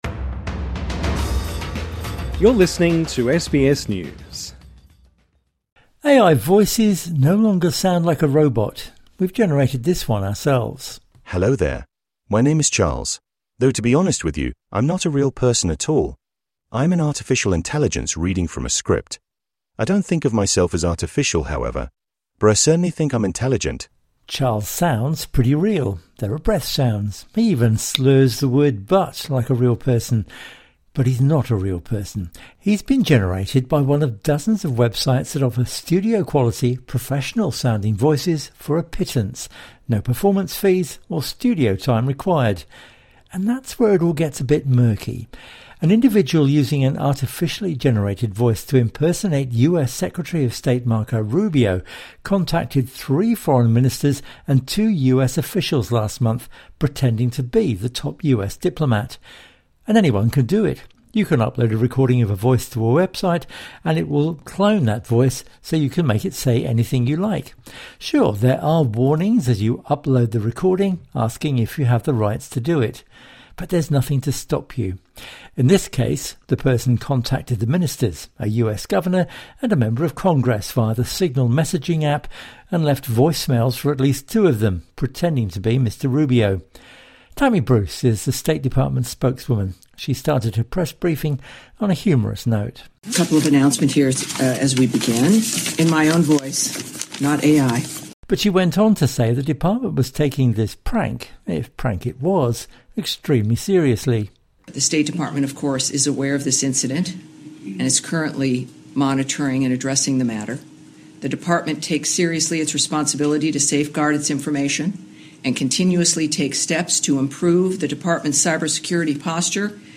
AI voices no longer sound like a robot.
Charles sounds pretty real. There are breath sounds. He even slurs the word 'but' like a real person.